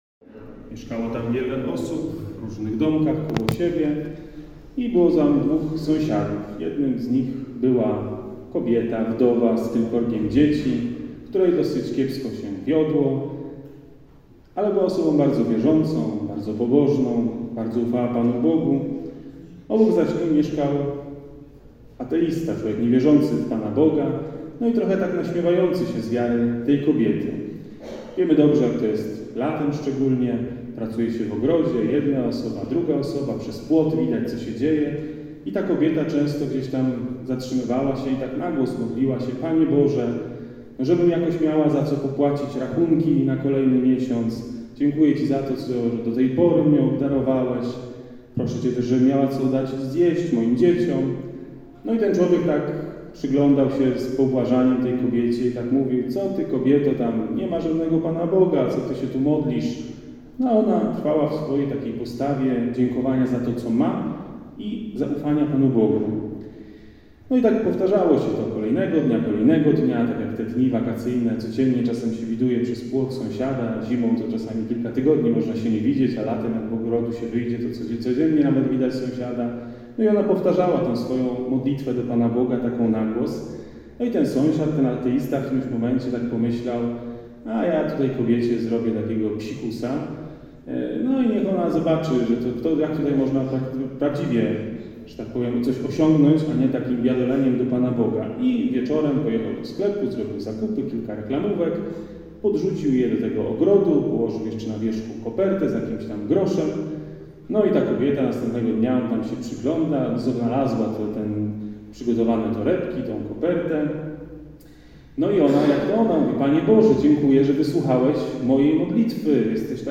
Dożynki rozpoczęły się Mszą Świętą z modlitwą dziękczynną za pracę rolników.
homilia-strzelce-dozynki-2022.mp3